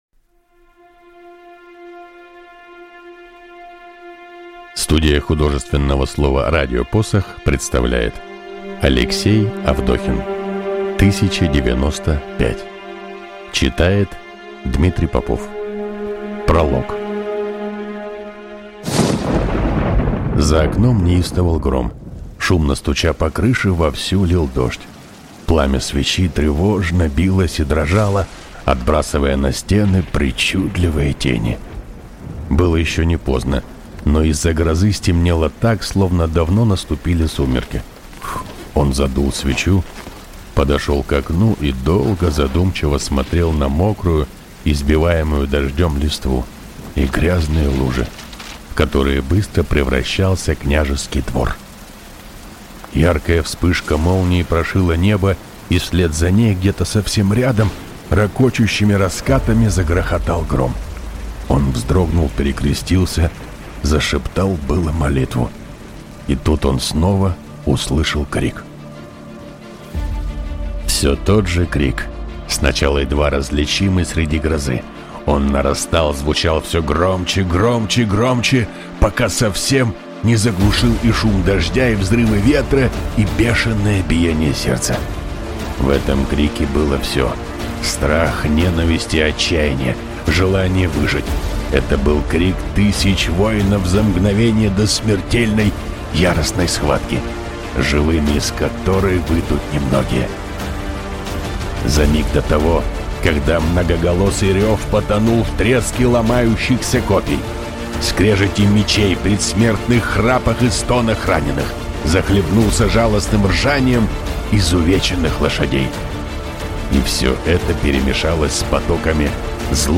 Аудиокнига 1095 | Библиотека аудиокниг